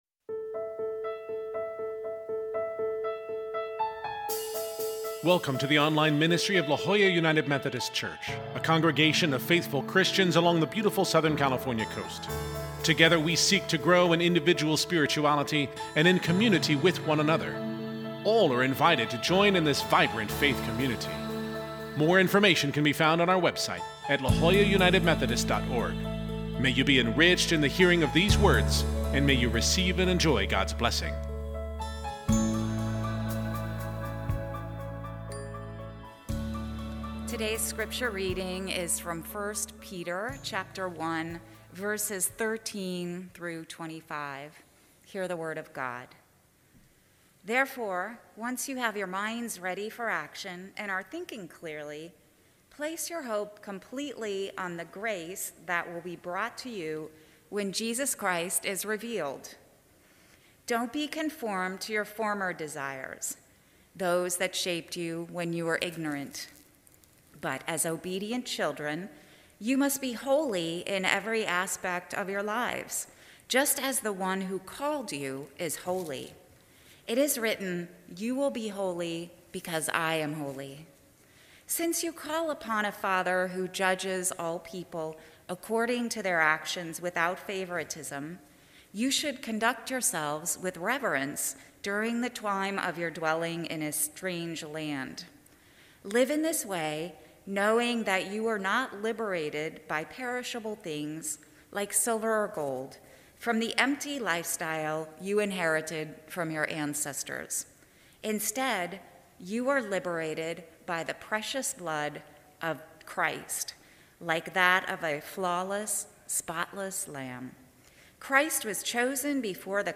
Scripture: 1 Peter 1:13-25 (CEB) Worship Bulletin Sermon Note Share this: Print (Opens in new window) Print Share on X (Opens in new window) X Share on Facebook (Opens in new window) Facebook